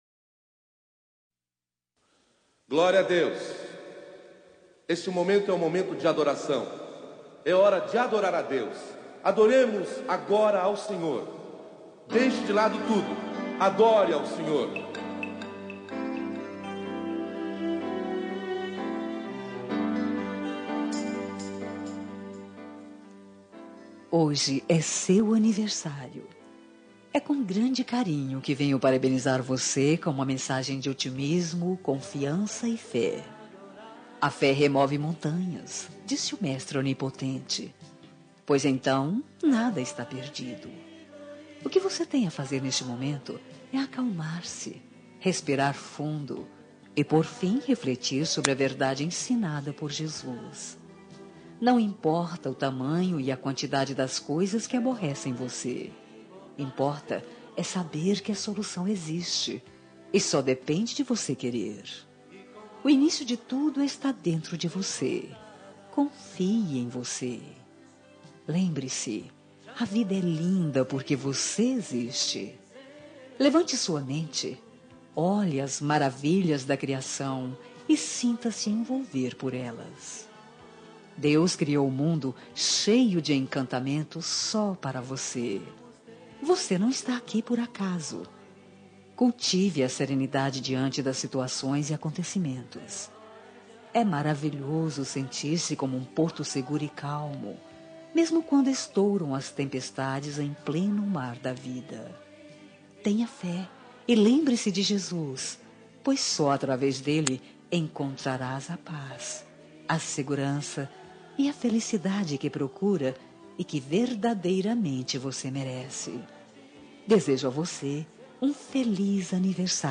Telemensagem de Aniversário de Pessoa Especial – Voz Feminina – Cód: 1893 Com Otimismo
1893-evangelica-com-otimismo-aniver.m4a